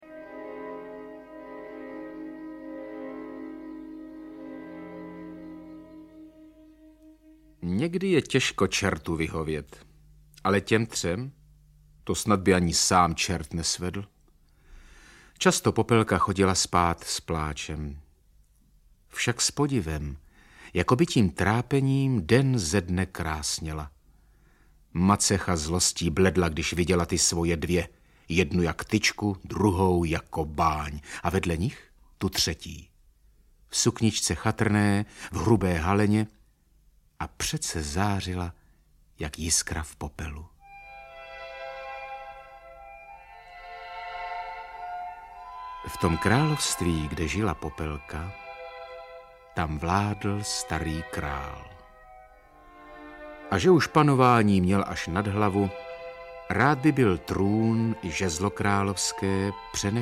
Audiobook
Read: Taťjana Medvecká